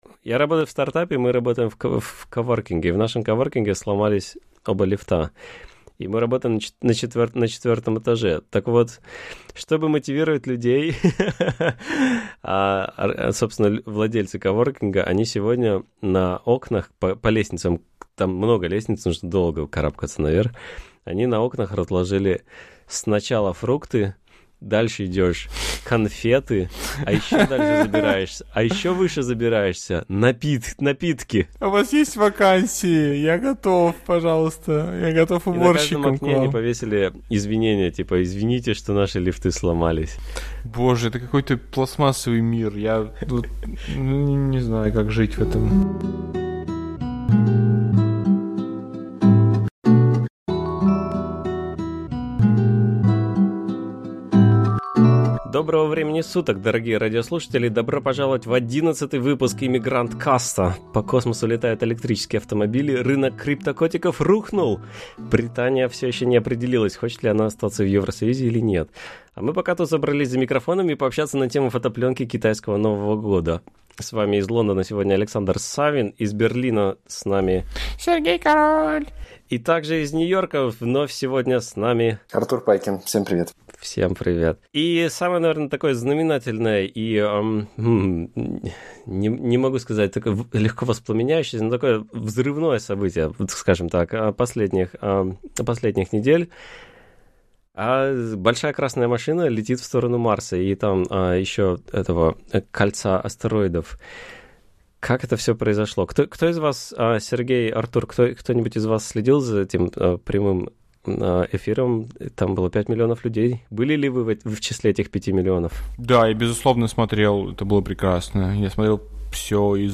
Подкаст о жизни в Лондоне, Берлине и Нью-Йорке, пленочной фотографии, инди-интернете, лодках, брекзите и дронах. На проводе гости от Сингапура и Гоа до Франции и США — от дизайнера шрифтов до пилота самолета.